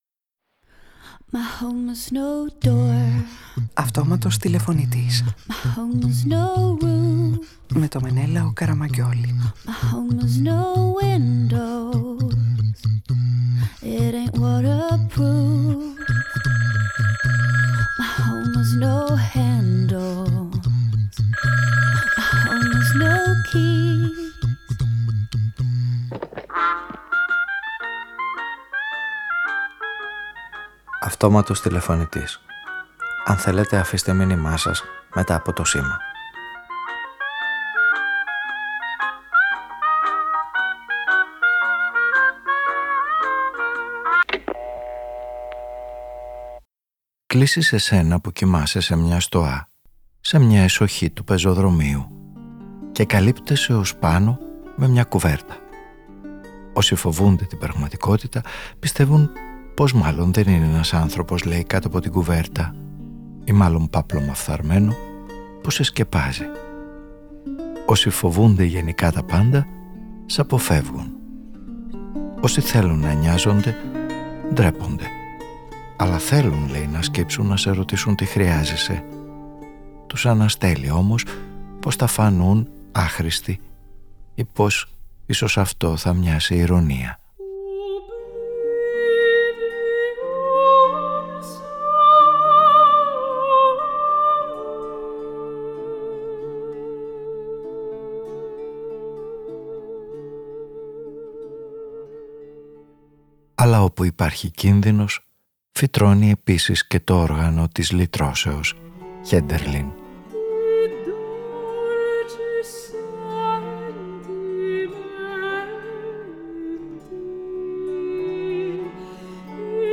Παραγωγή-Παρουσίαση: Μενέλαος Καραμαγγιώλης
Ραδιοφωνικη Ταινια